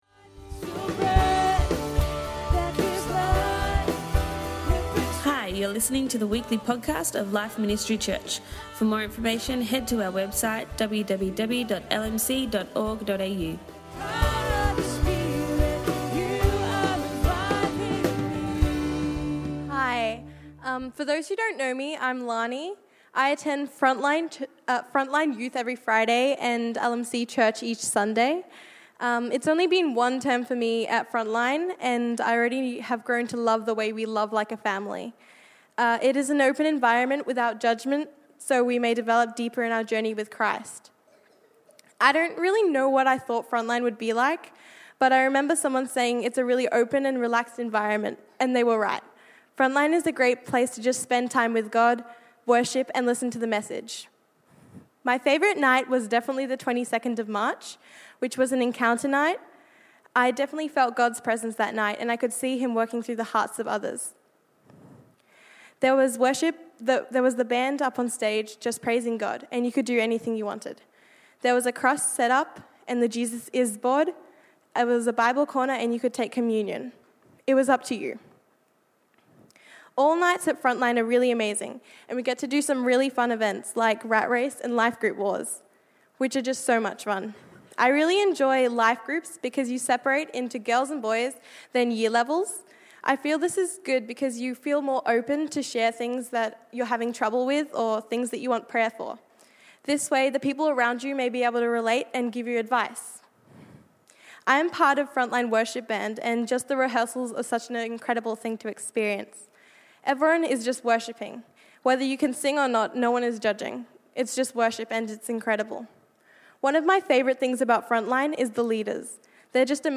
Youth Service - Mary, Martha, and Jesus